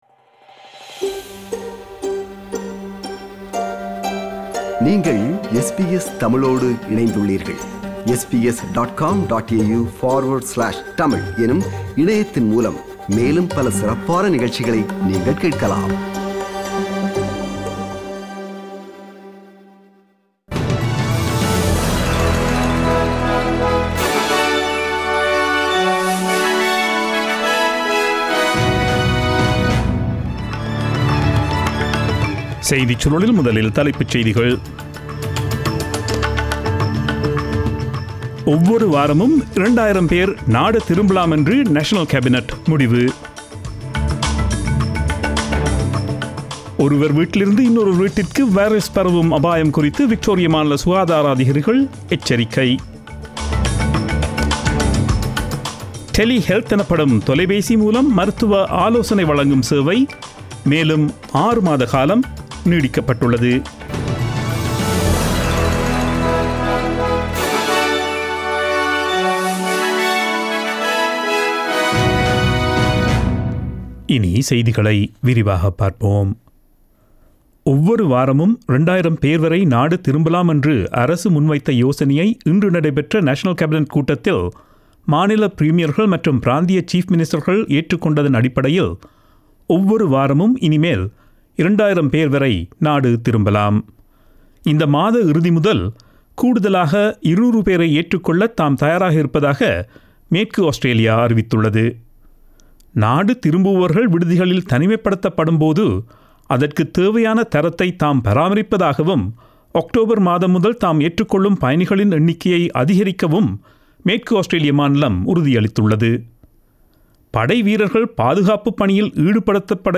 Australian news bulletin aired on Friday 18 September 2020 at 8pm.